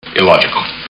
Spock
Spock_Illogical.mp3